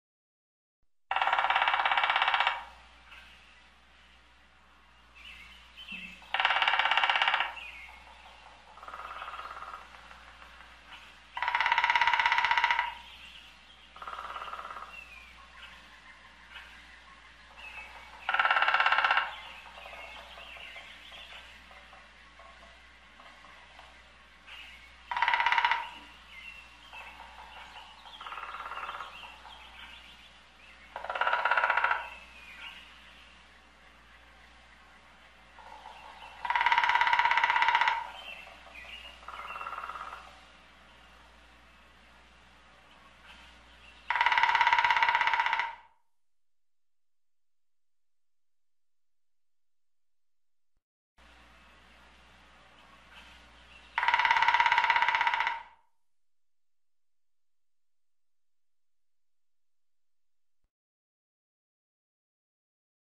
دانلود صدای دارکوب از ساعد نیوز با لینک مستقیم و کیفیت بالا
جلوه های صوتی